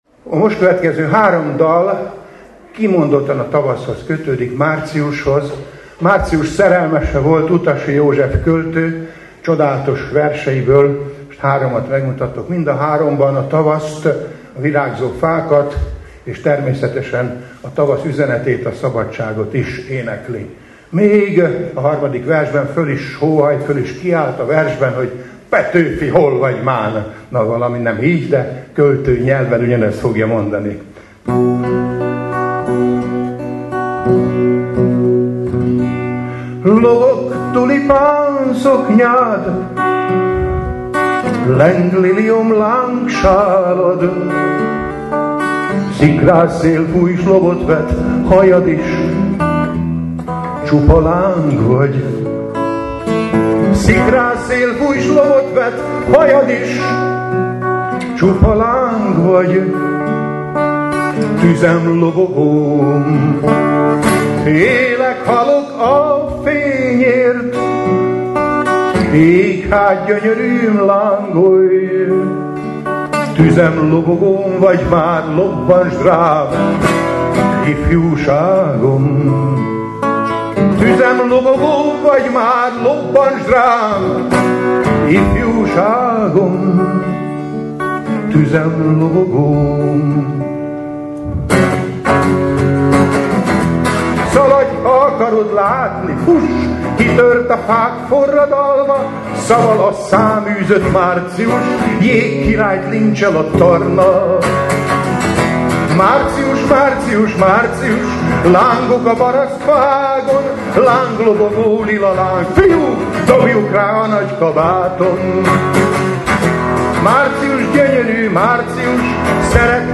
Majd következtek a dalok, amelyek eléneklésébe a közönséget is bevonta, nem kis sikerrel, ekképpen: